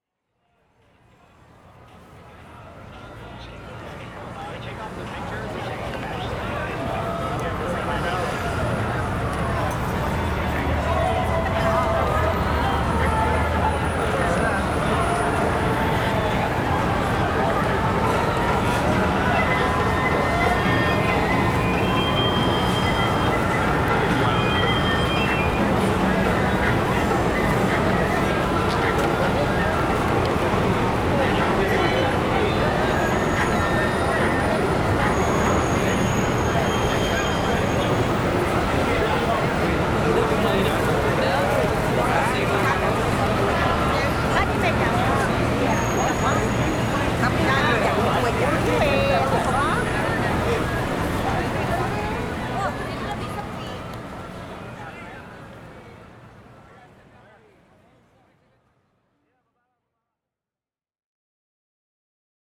Rajouter de l’ambiance
Une piste du Japon (une rue commerçante d’Osaka) et une piste de New York avec encore des sirènes. Cette dernière est placée sur la droite où je trouvais qu’il y avait un petit manque, et cela ajoute de la profondeur en plus. La piste d’Osaka, elle, commence plus tard et apporte une super musique traditionnelle vers 20 secondes, ça ajoute beaucoup au coté Voyage.
04-Ajout-dambiances.wav